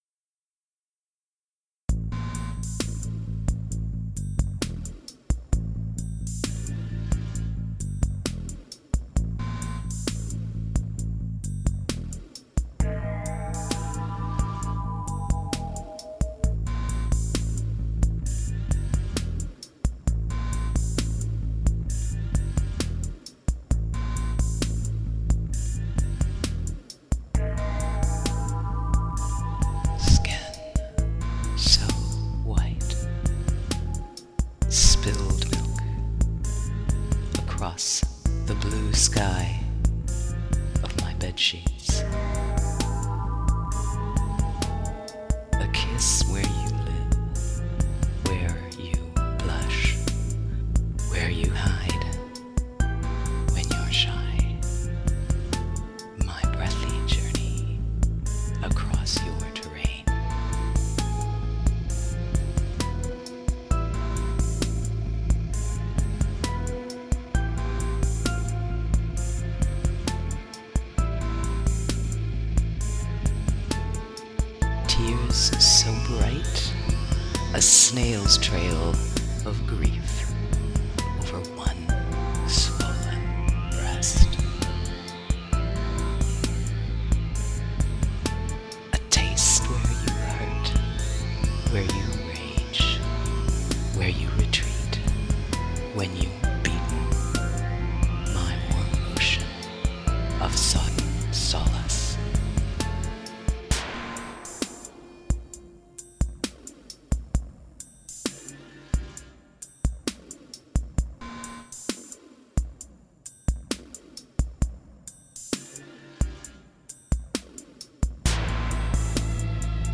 It’s really rough, but you kind of get the idea.
Hypnotic.
The blend of ethereal and eerie background mixed with the smoky vocals gave me shivers.